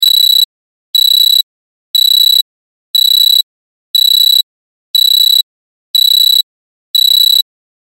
دانلود صدای هشدار 7 از ساعد نیوز با لینک مستقیم و کیفیت بالا
جلوه های صوتی